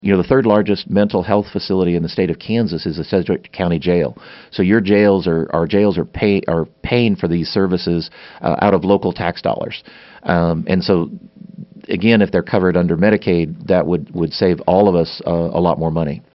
Lt. Gov. Lynn Rogers in-studio for KMAN's In Focus, Wednesday, April 3, 2019.